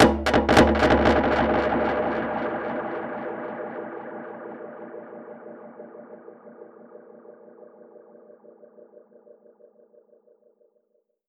Index of /musicradar/dub-percussion-samples/85bpm
DPFX_PercHit_C_85-02.wav